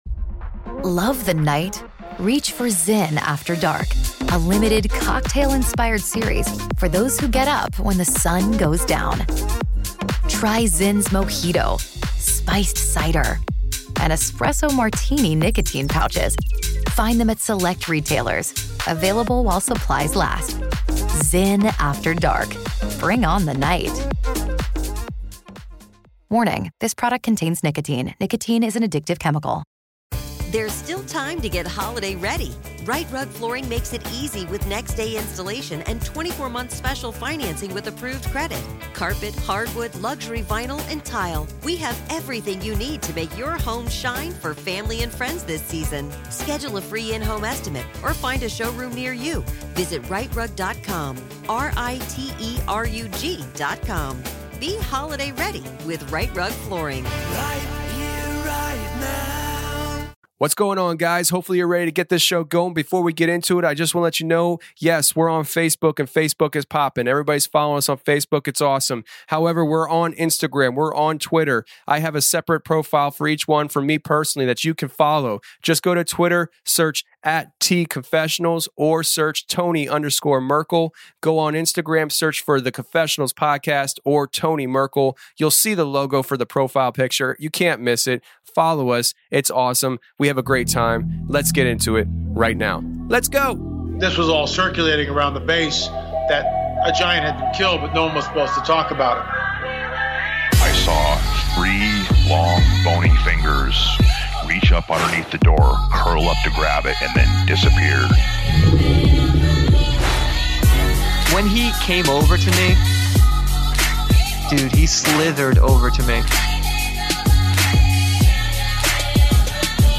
Show Intro INSTRUMENTAL